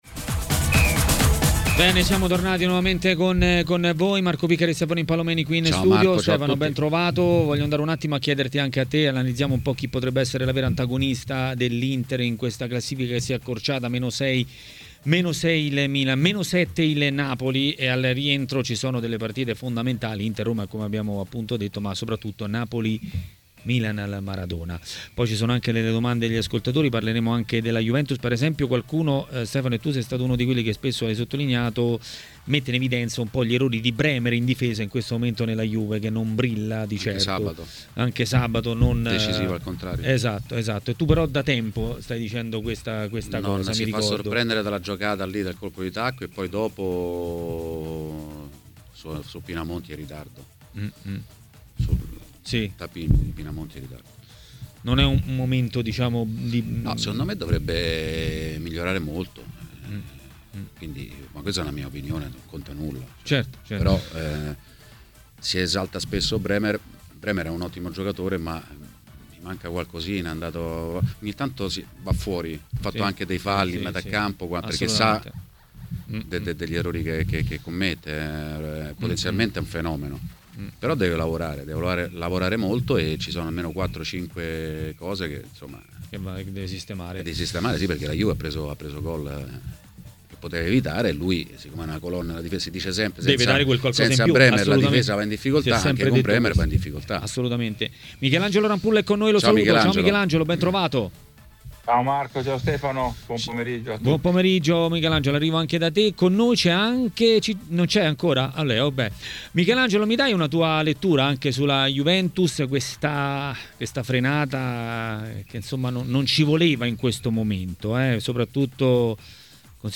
L'ex portiere Michelangelo Rampulla è intervenuto a TMW Radio, durante Maracanà.